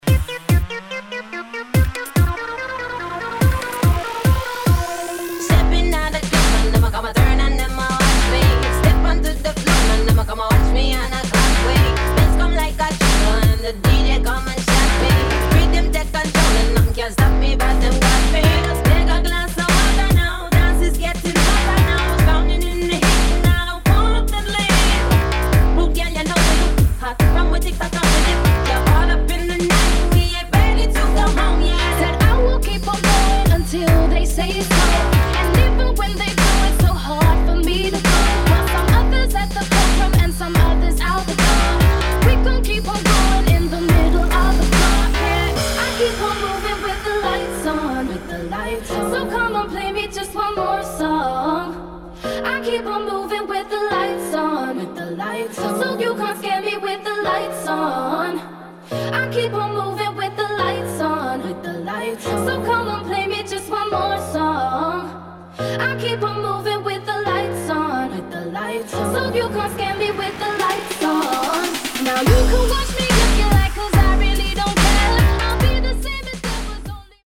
[ DUBSTEP / UK GARAGE ]